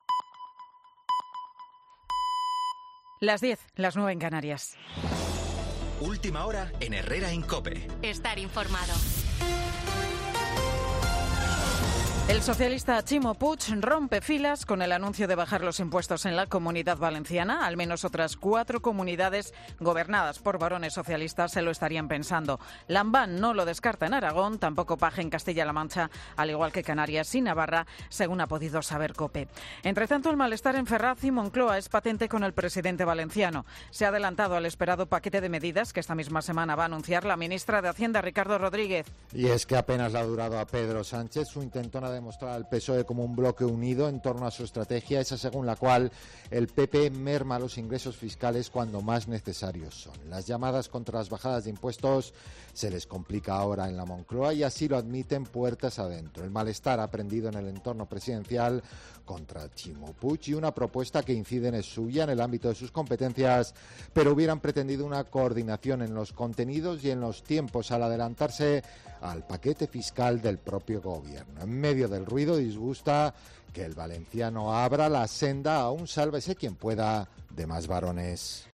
Pincha aquí para escuchar la crónica